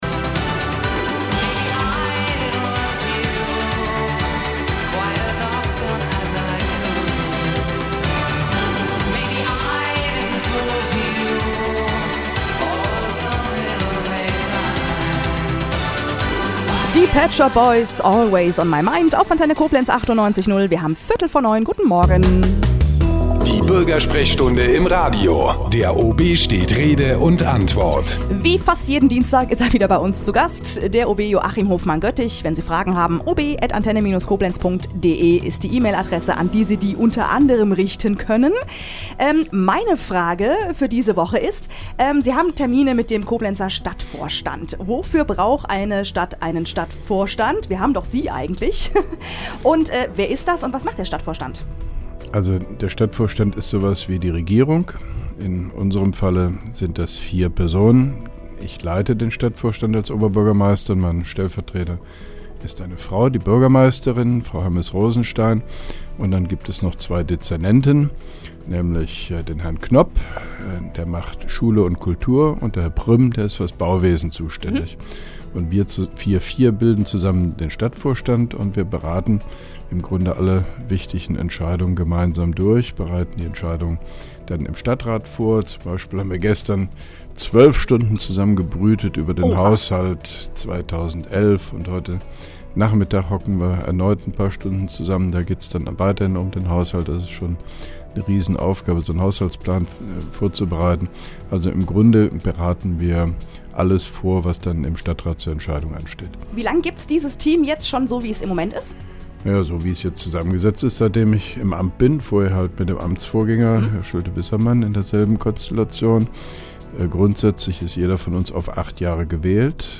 (3) Koblenzer OB Radio-Bürgersprechstunde 23.11.2010
Interviews/Gespräche